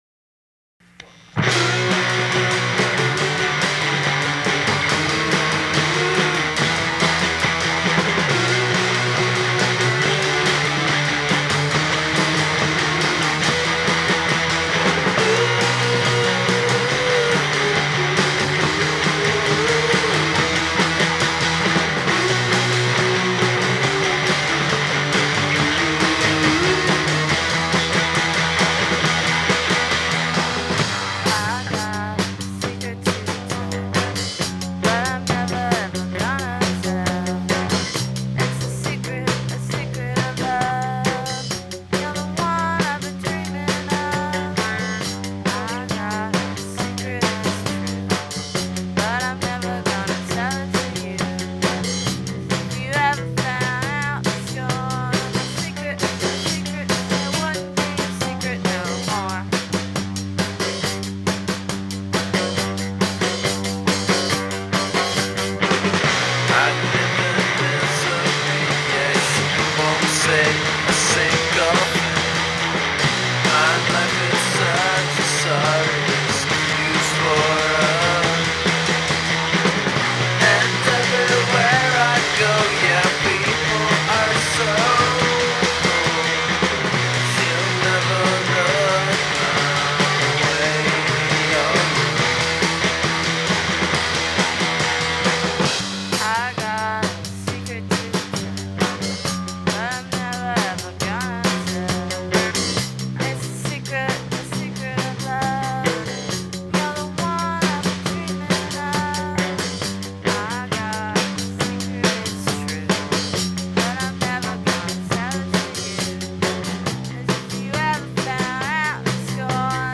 indie rock